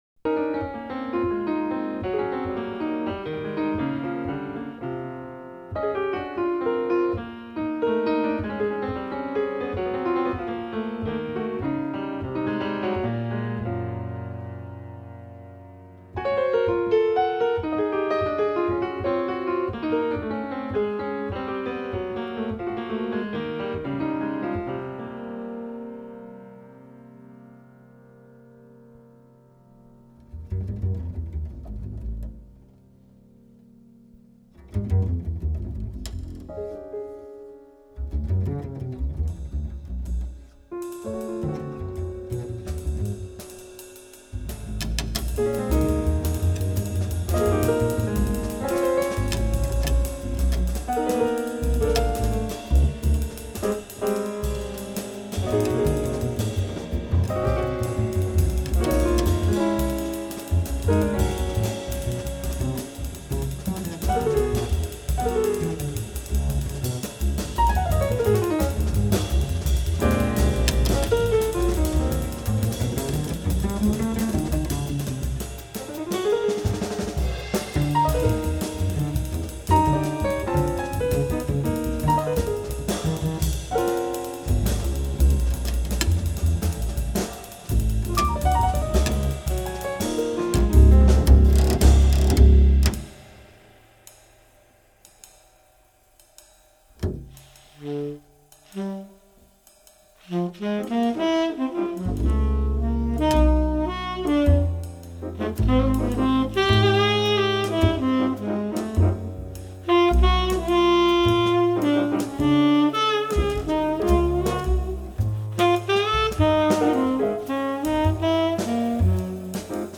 alto sax
pianoforte
batteria.